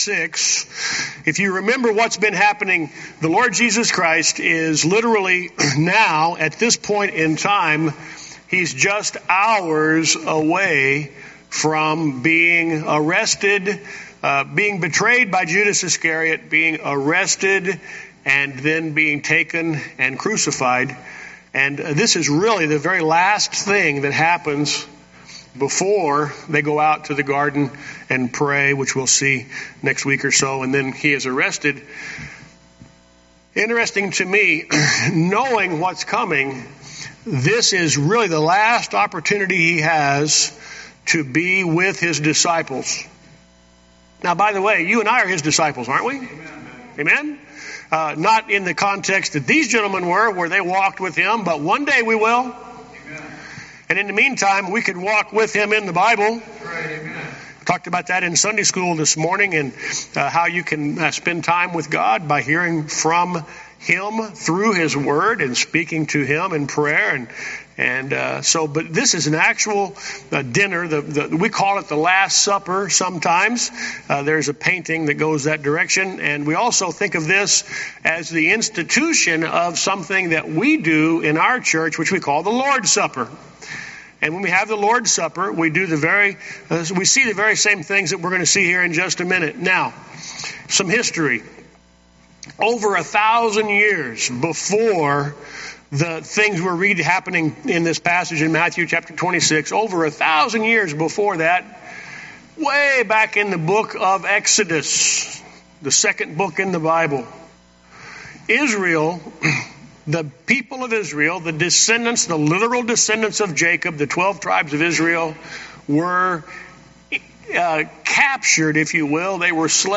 Guest Speaker